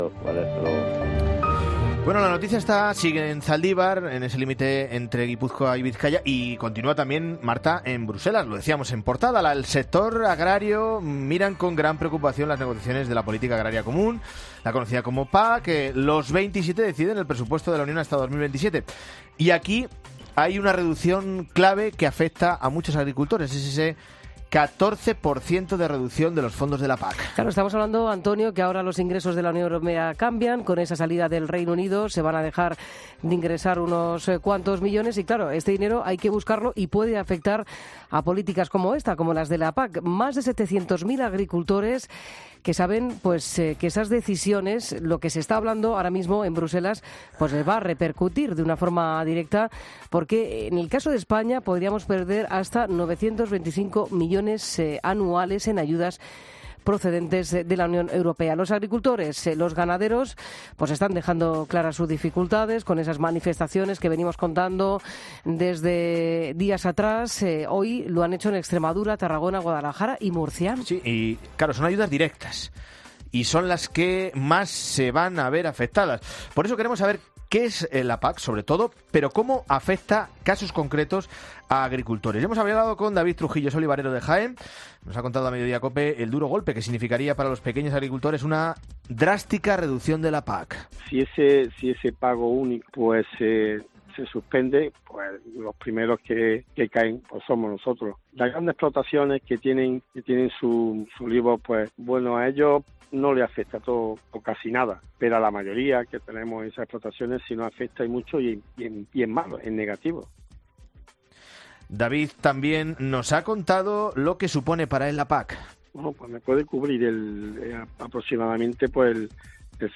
En Mediodía COPE les han preguntdo cómo les podría afectar un recorte de las ayudas que reciben a través de la PAC.